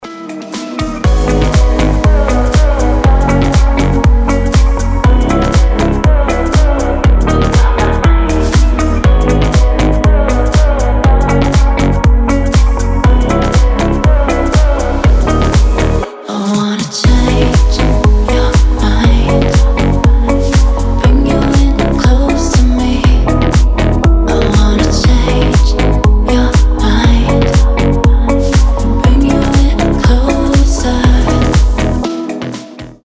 • Качество: 320, Stereo
красивые
женский вокал
Стиль: deep house